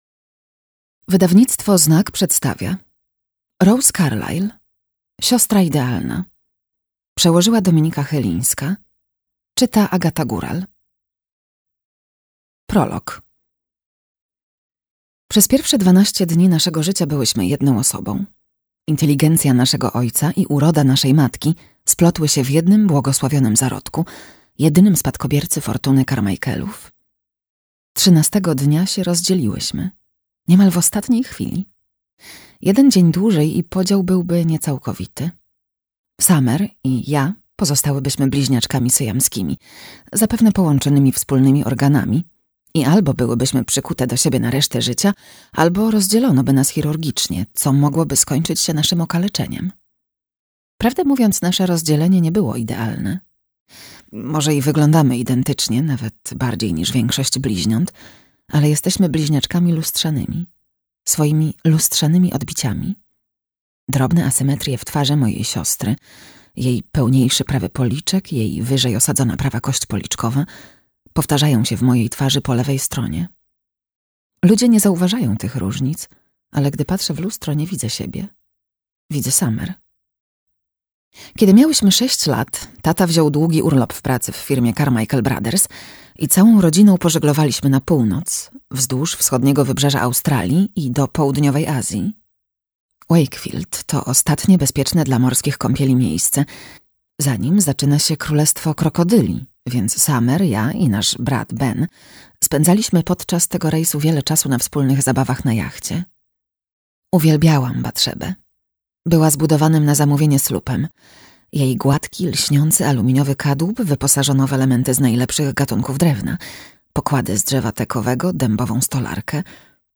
Audiobook Siostra idealna, Carlyle Rose.